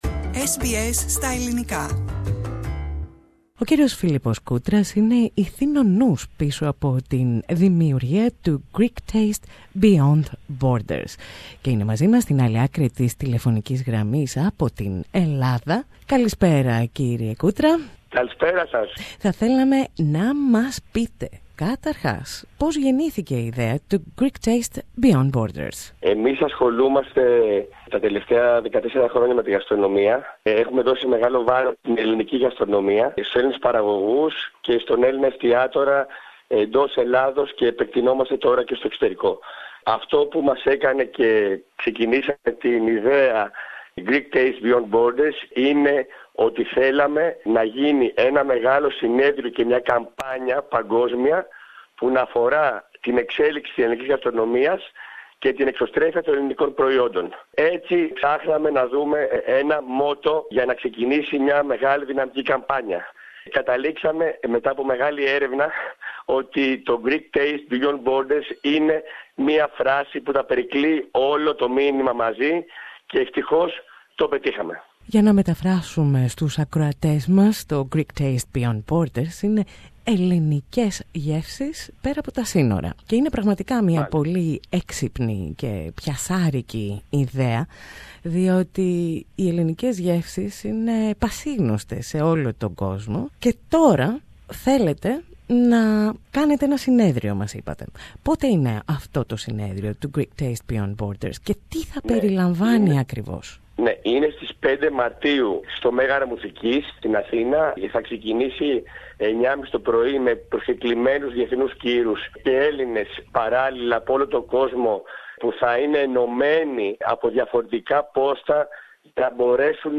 SBS Greek